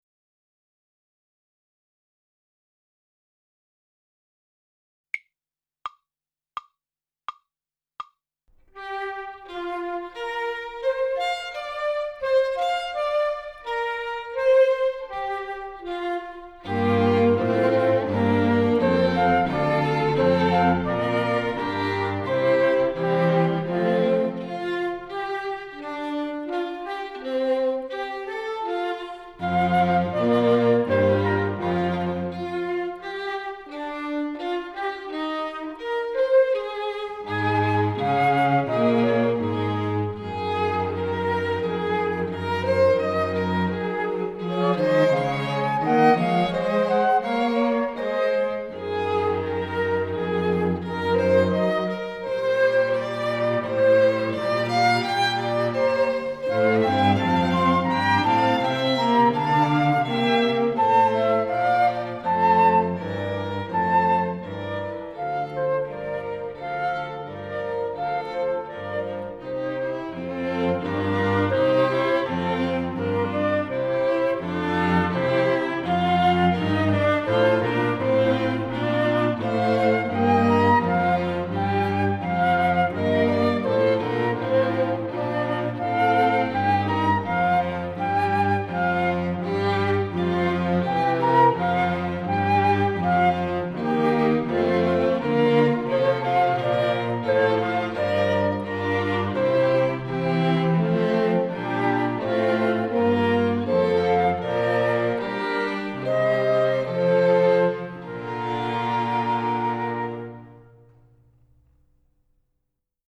Besetzung: Instrumentalnoten für Flöte